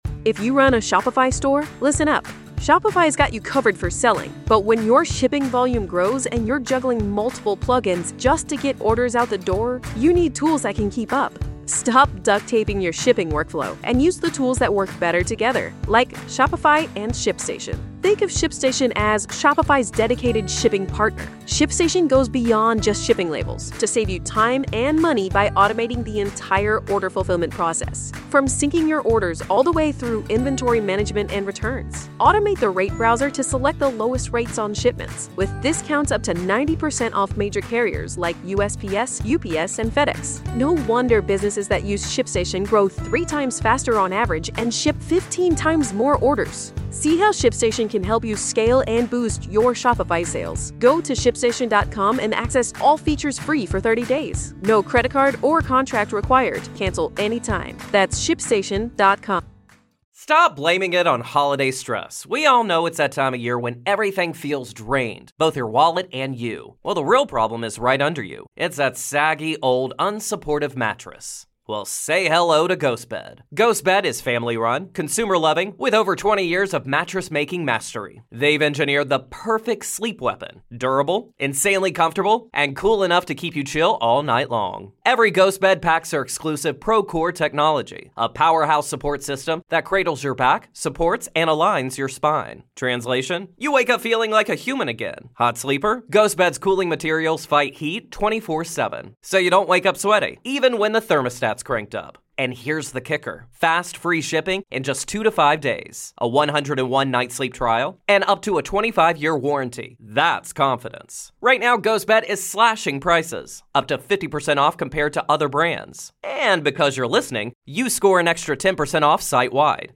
History and hauntings collide in this captivating discussion. This is Part Two of our conversation.